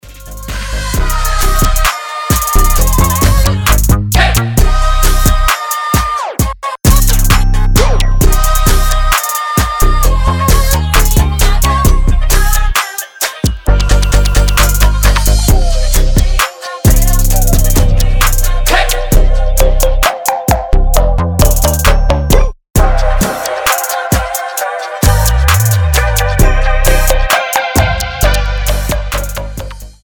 • Качество: 320, Stereo
ритмичные
мелодичные
Electronic
басы
звонкие
Классный мелодичный трапчик